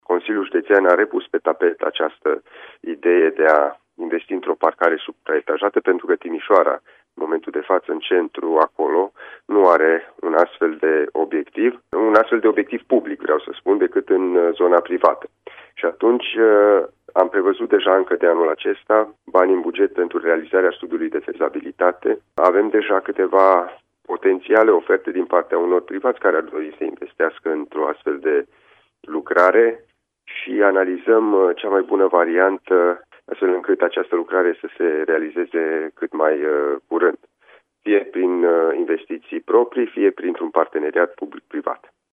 Actualul președinte al CJ Timiș, Alin Nica, a precizat la Radio Timișoara, că investiția va fi realizată fie din fonduri proprii, fie în parteneriat public – privat.